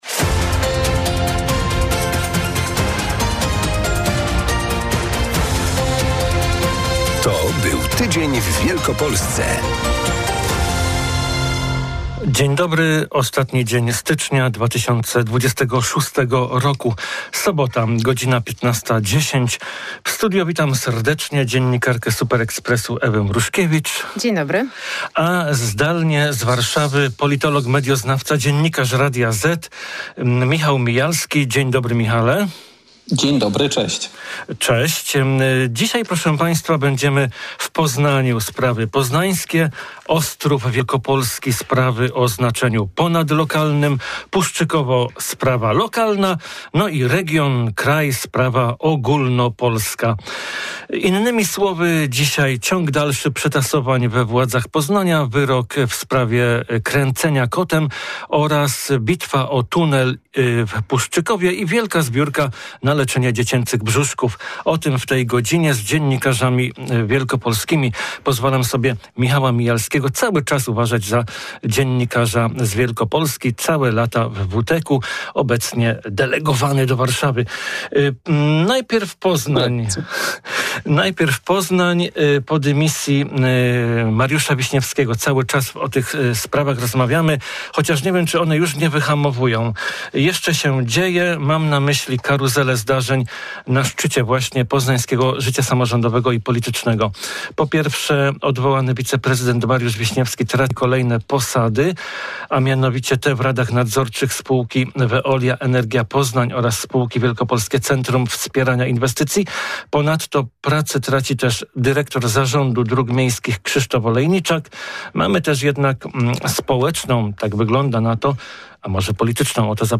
Następnie zajęła nas bitwa o tunel w podpoznańskim Puszczykowie (już druga taka w XXI wieku!). Na finał dziennikarskiej debaty wzięliśmy 34. finał WOŚP.